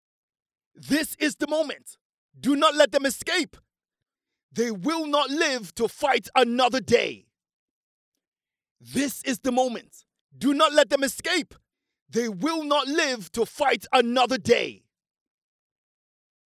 Male
English (South African)
Adult (30-50)
i have an energetic yet adaptable voice .full of life
Studio Quality Sample
0828Audition_for_Sci-fi_series.mp3